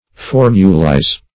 Search Result for " formulize" : The Collaborative International Dictionary of English v.0.48: Formulize \For"mu*lize\, v. t. [imp.
formulize.mp3